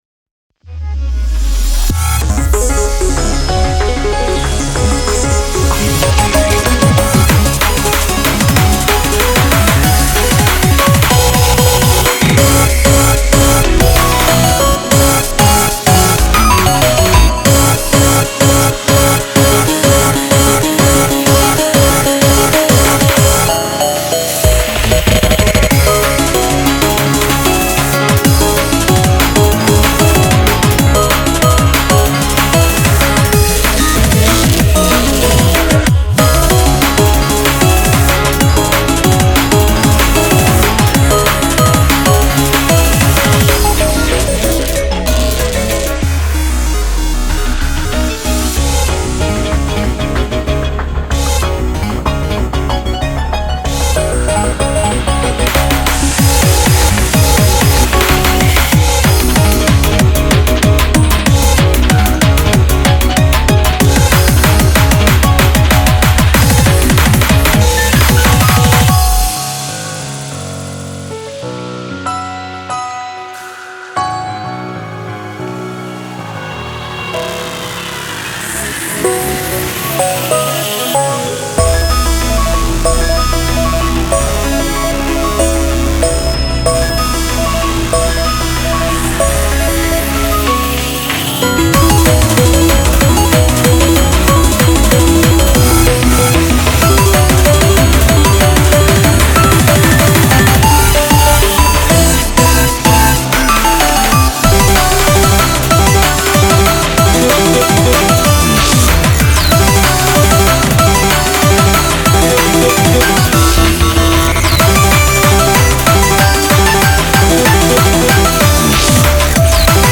BPM189
Audio QualityPerfect (High Quality)
Genre: Artcore